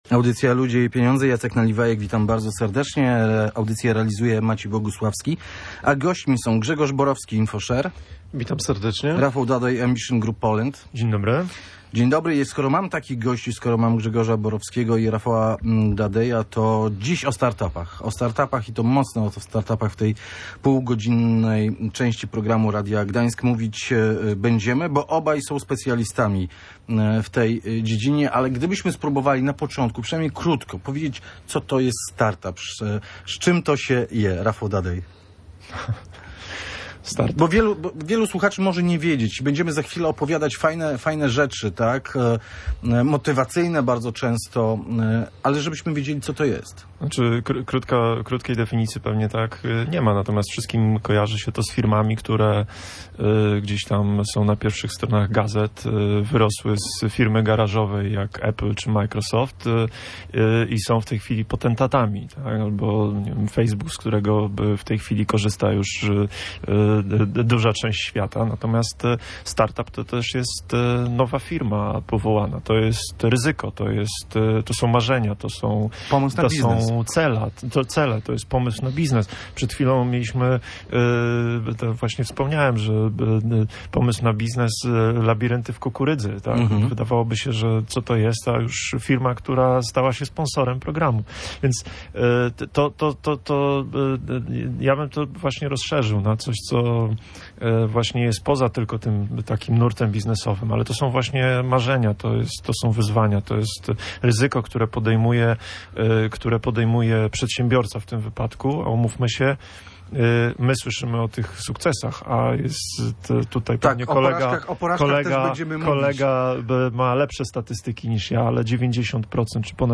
Jakie są uniwersalne przepisy? O tym mówili nasi eksperci.